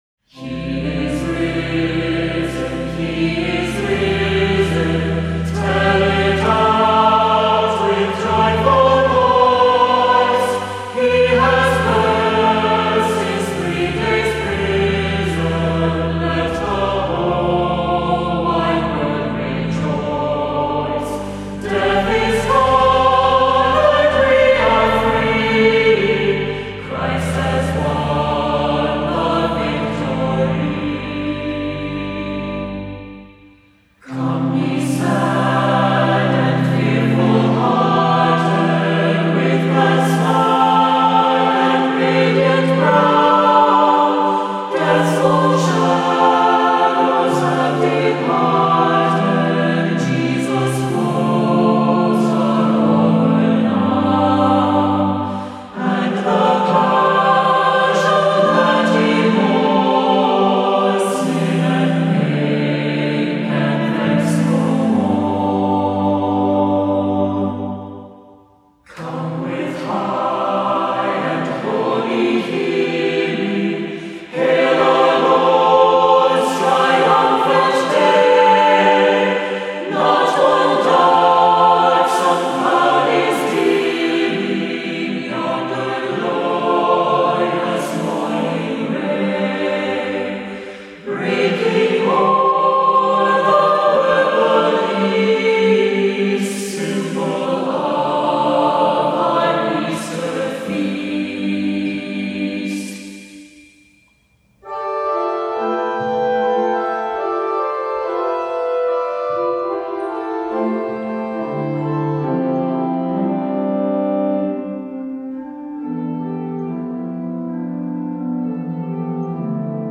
Voicing: SATB; Assembly; Descant